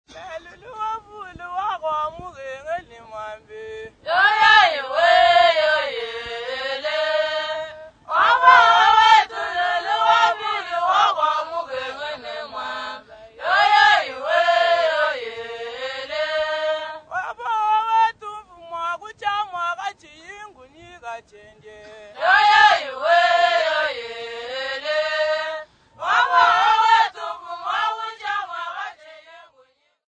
Lulua Women
Folk music--Africa
Field recordings
Africa Zambia Not specified f-za
sound recording-musical